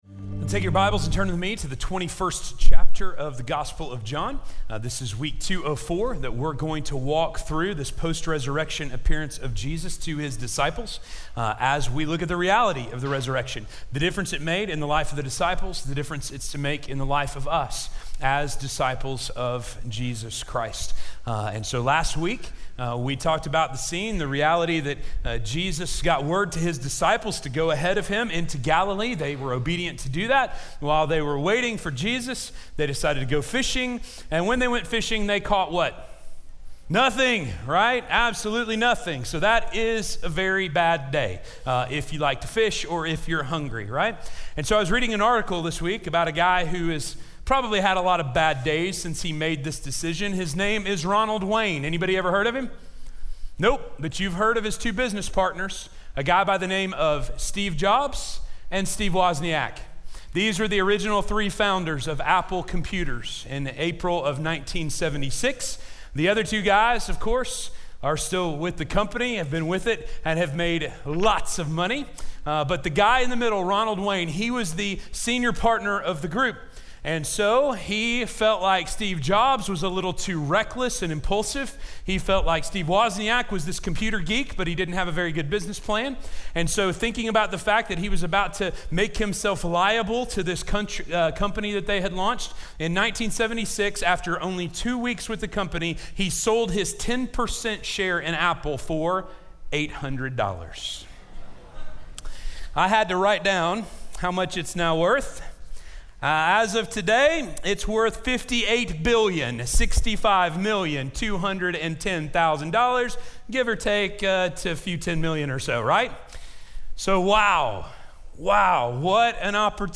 Do You Love Me More? - Sermon - Station Hill